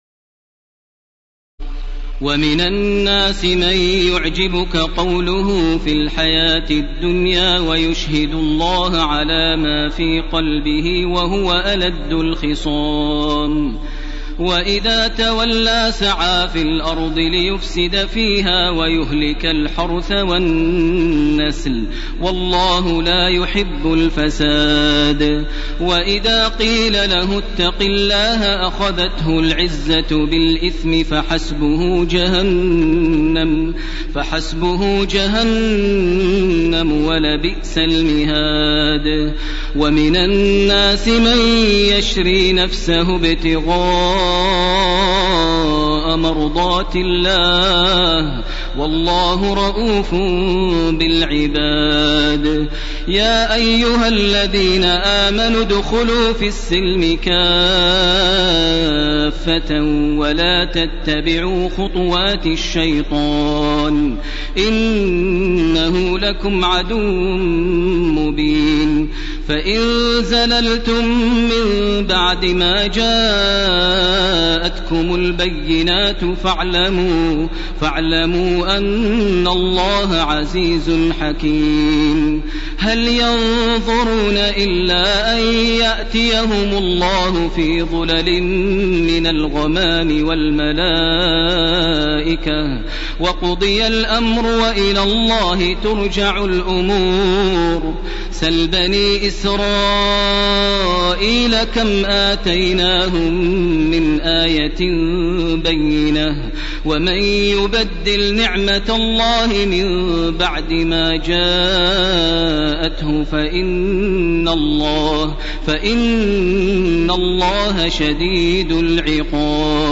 تراويح الليلة الثانية رمضان 1431هـ من سورة البقرة (204-252) Taraweeh 2 st night Ramadan 1431H from Surah Al-Baqara > تراويح الحرم المكي عام 1431 🕋 > التراويح - تلاوات الحرمين